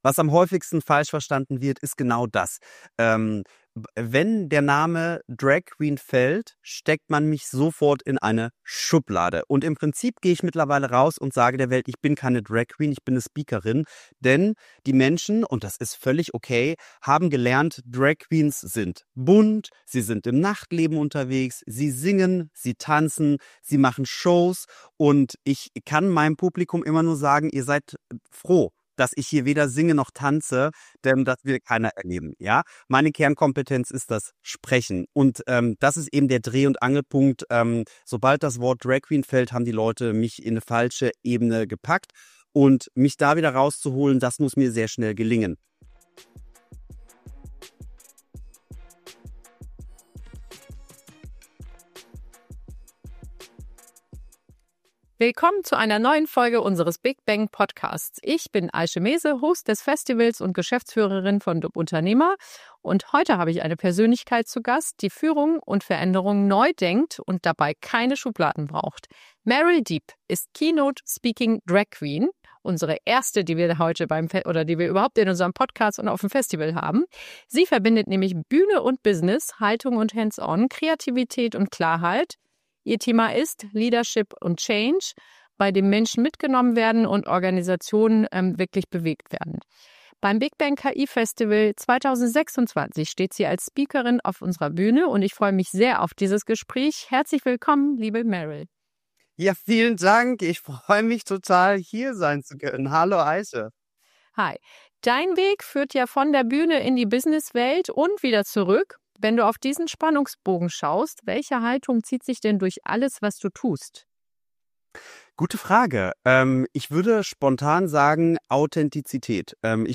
Ein Gespräch über Mut als Muskel, Change als Dauerzustand und Leadership, das Menschen stärkt, statt Prozesse verhärtet.